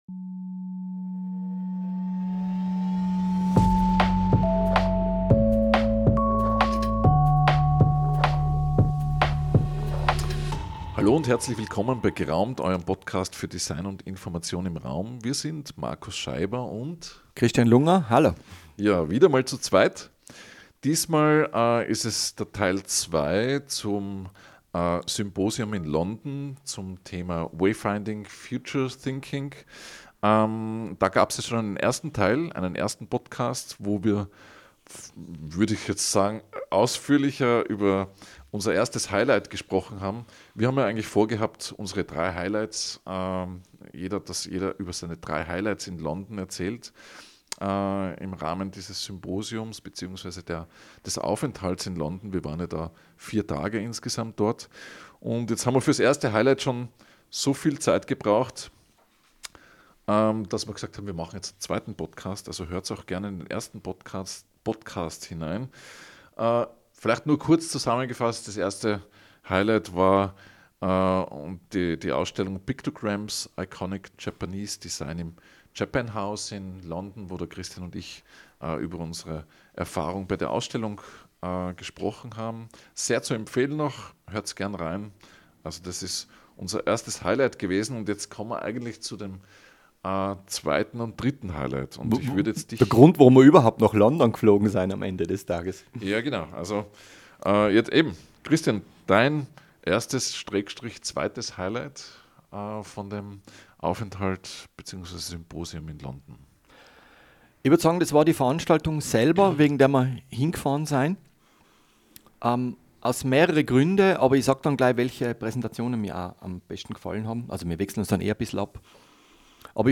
Informative Gespräche geben Einblick in die Welt der räumlichen Orientierung von Menschen in Gebäuden, Orten und im Naturraum.